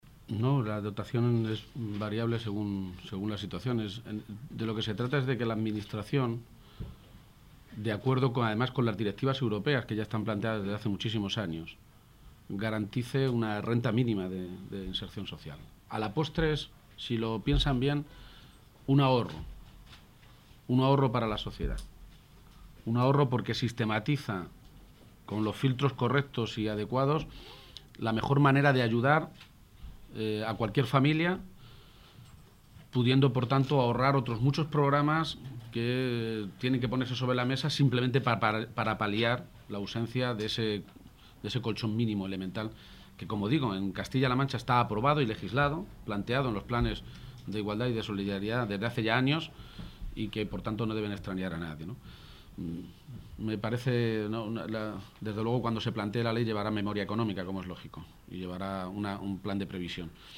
García-Page se pronunciaba de esta manera esta mañana, en Toledo, en declaraciones a los medios de comunicación durante la firma de un convenio con Cruz Roja.
Cortes de audio de la rueda de prensa